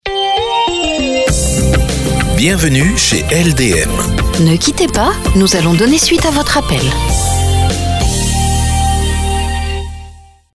Message répondeur professionnel